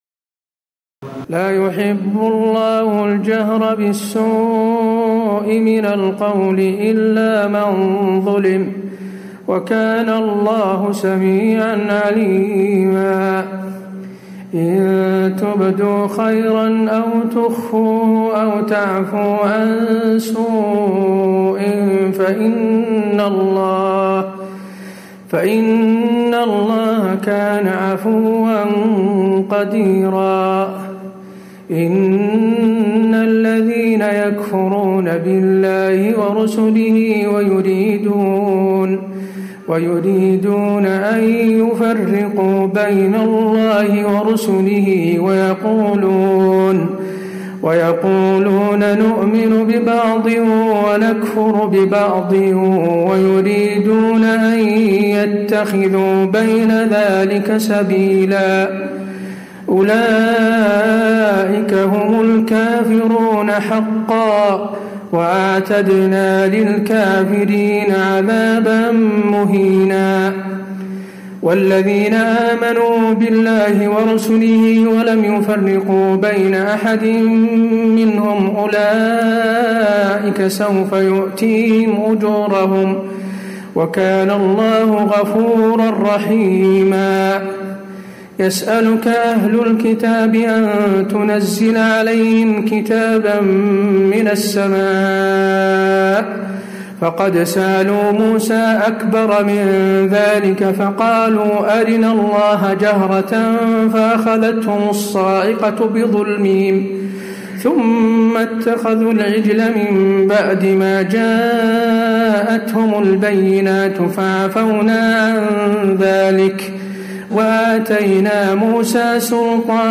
تراويح الليلة السادسة رمضان 1436هـ من سورتي النساء (148-176) و المائدة (1-26) Taraweeh 6 st night Ramadan 1436H from Surah An-Nisaa and AlMa'idah > تراويح الحرم النبوي عام 1436 🕌 > التراويح - تلاوات الحرمين